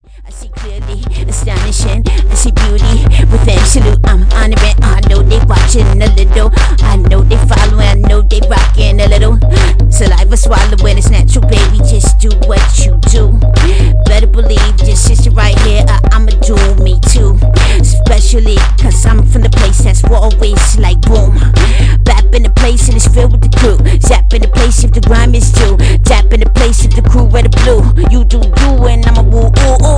gives a fun music experience while infusing edm and hiphop